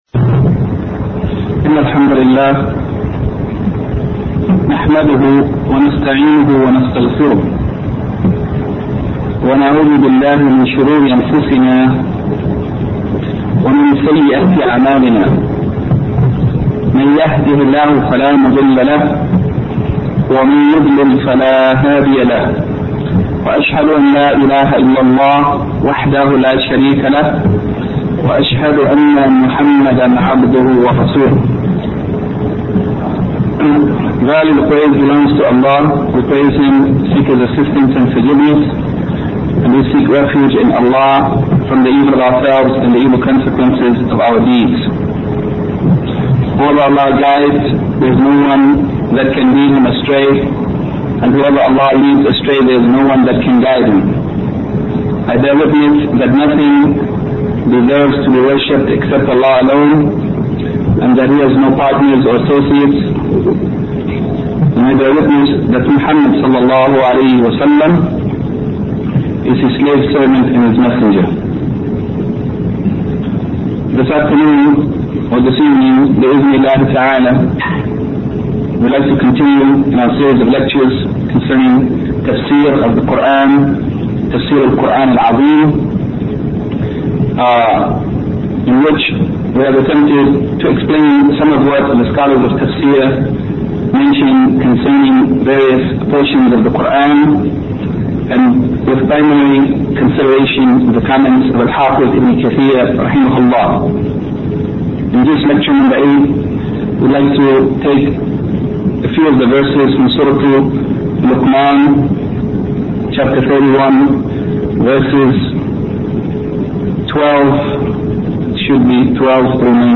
Tafseer: Ibraaheem’s Dua’ for Makkah 14:35-41 - Du'aa Ibraaheem li-Makkah